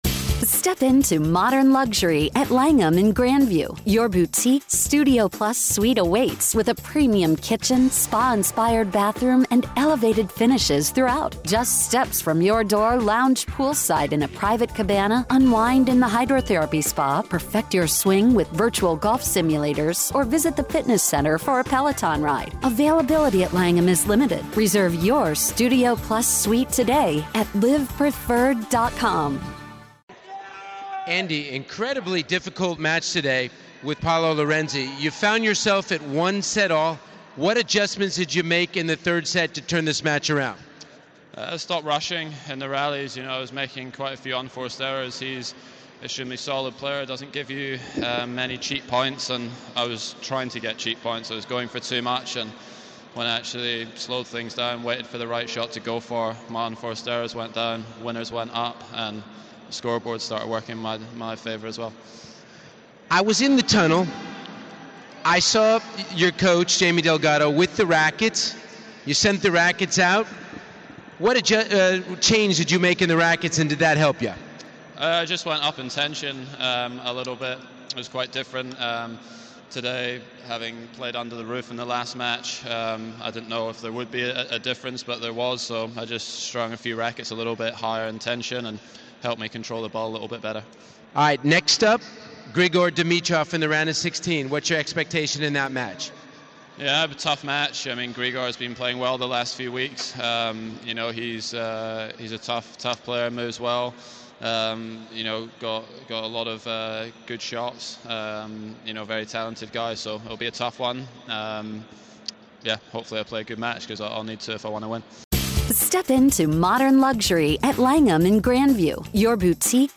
Andy Murray speaks following his victory over Paolo Lorenzi.